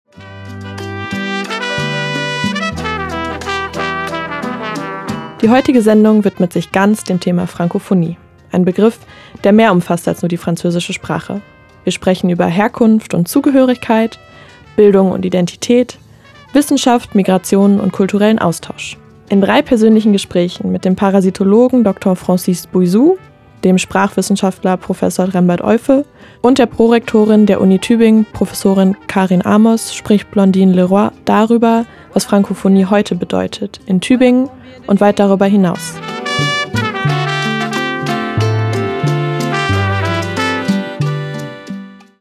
Drei Gespräche über Francophonie (643)
Teaser_643.mp3